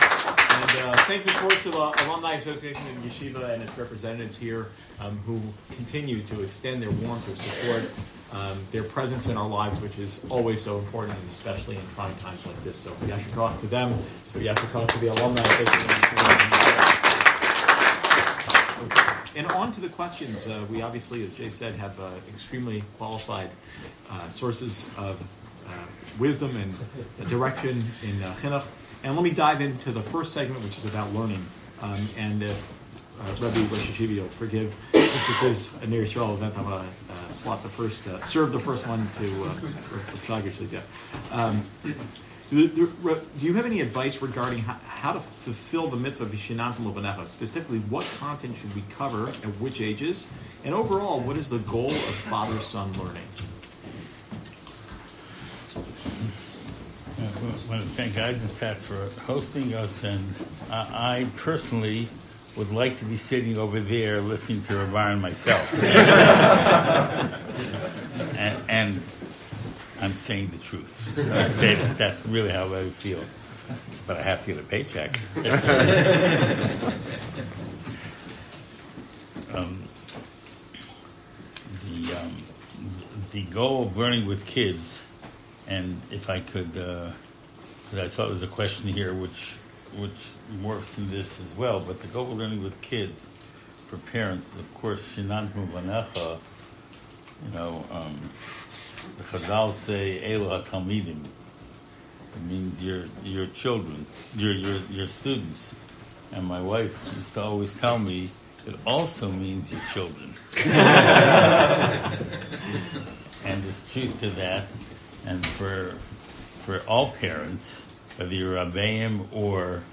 question and answer session